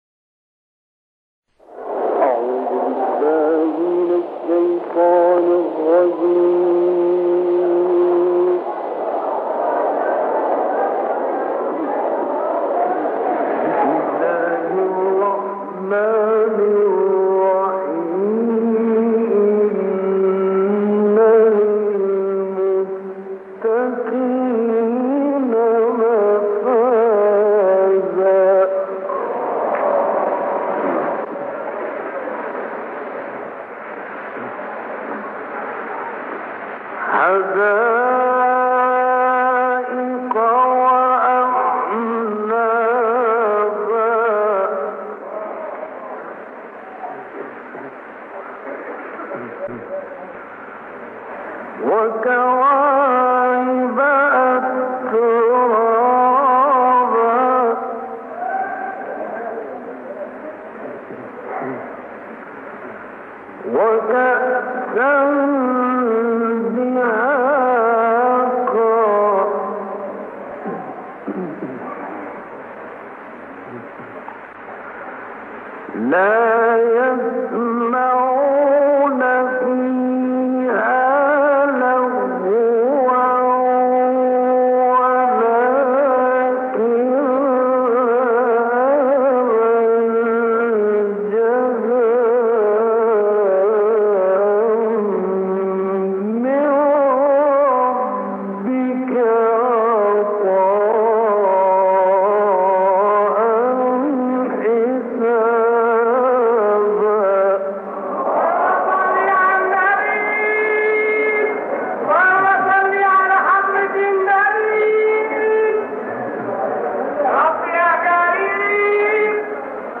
به گزارش خبرگزاری بین المللی قرآن(ایکنا)، مرحوم راغب مصطفی غلوش، قاری برجسته مصری در دهه 60 میلادی در مسجد امام حسین(ع) قاهره، سوره‌های نبا و نصر را تلاوت کرده است که به شاهکار غلوش معروف است و به نظر خودش، بهترین تلاوتی است که انجام داده و به‌ شدت تحت تاثیر فضای مجلس بوده است.